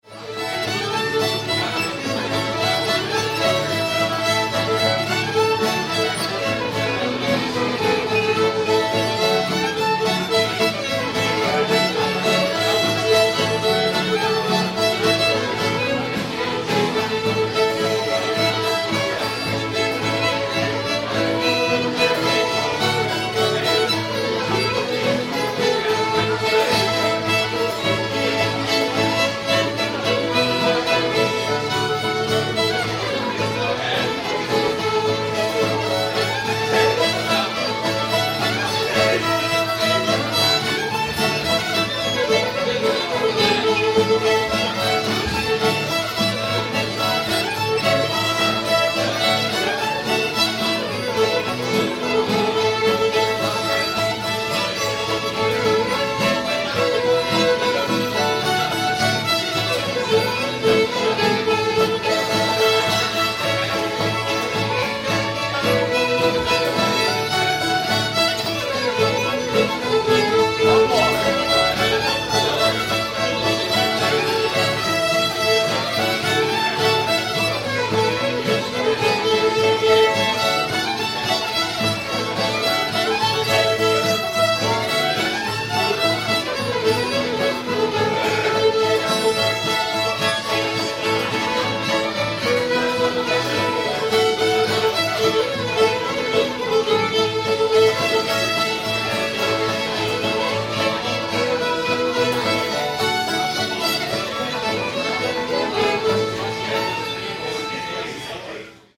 little dutch girl [A]